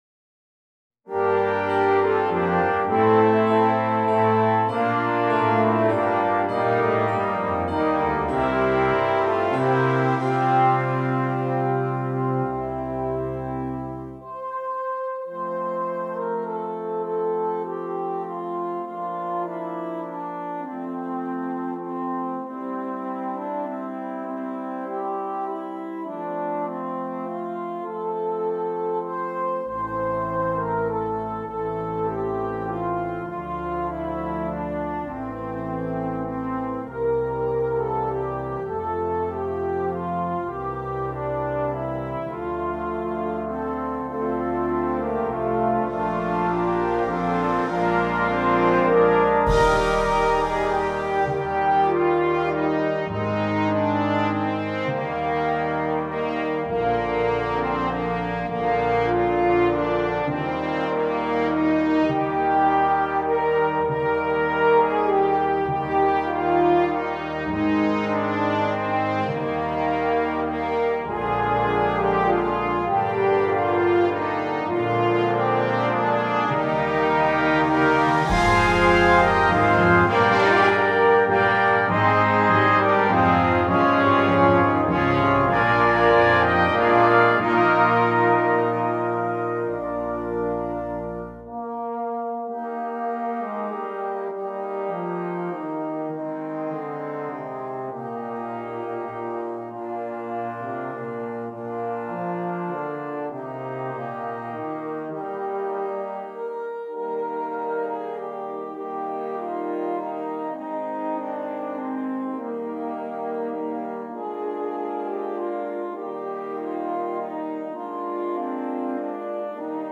Brass Choir
Traditional